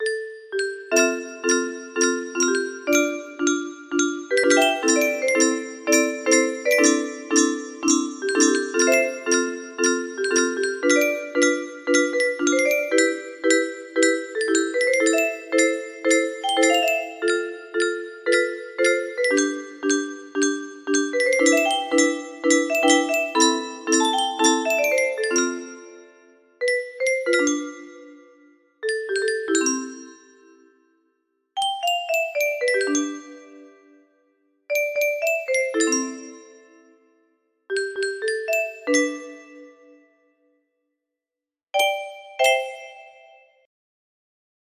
First verse + chorus excluding the bridge